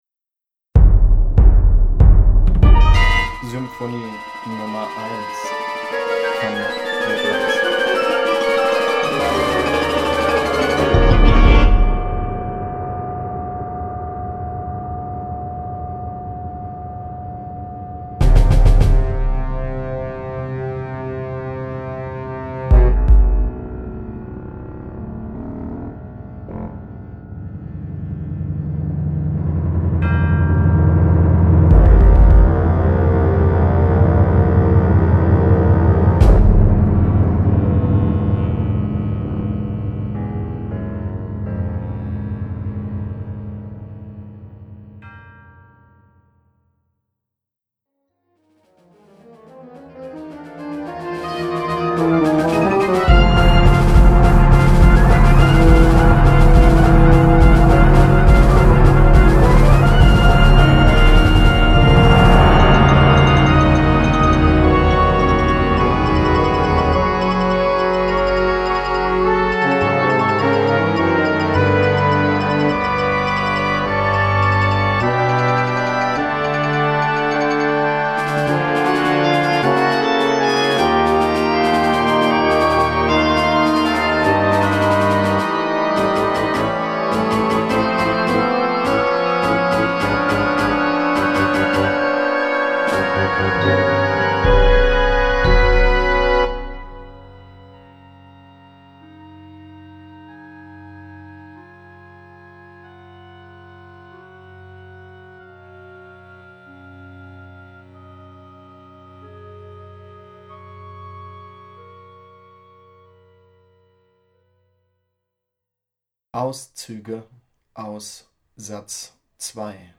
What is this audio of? Works for Concertband: